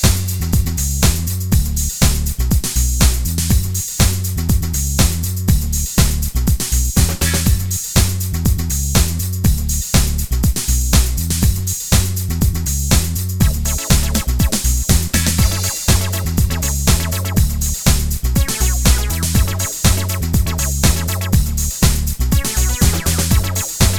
no Backing Vocals Soundtracks 5:37 Buy £1.50